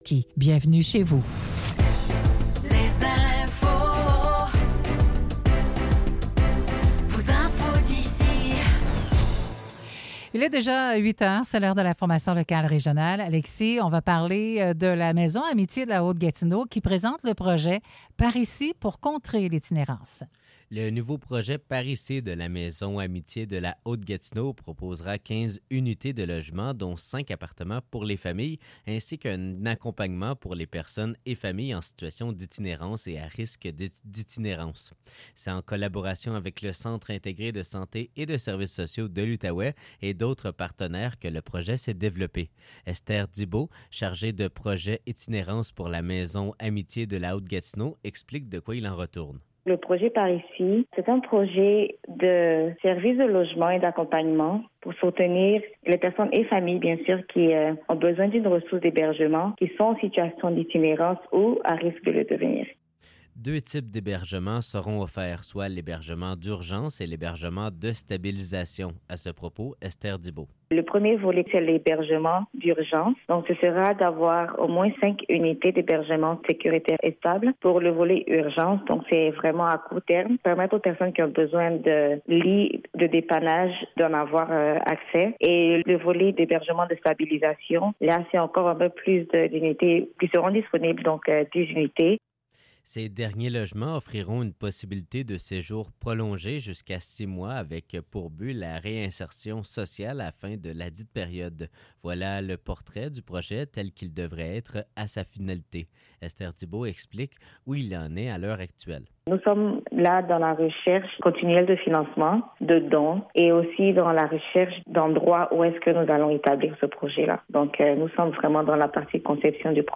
Nouvelles locales - 15 juillet 2024 - 8 h